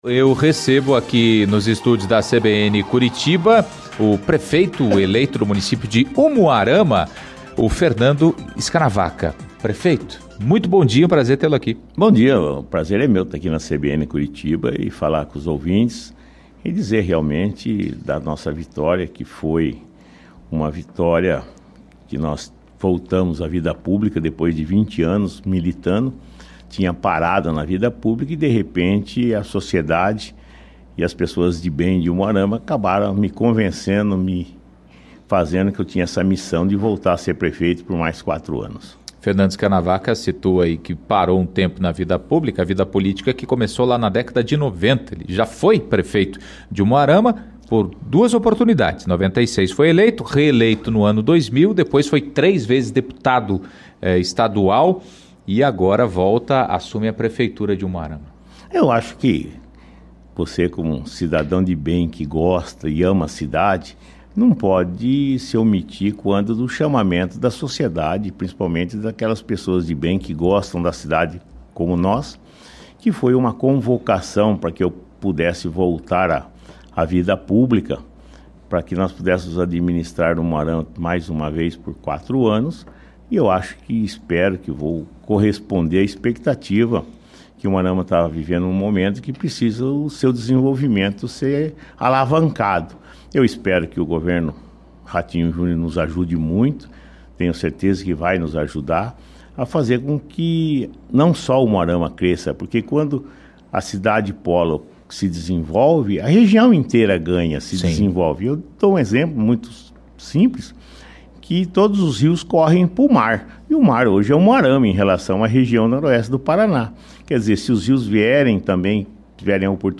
O prefeito eleito de Umuarama, no noroeste do estado, Fernando Scanavaca (Republicanos), visitou a CBN Curitiba nesta quarta-feira (30).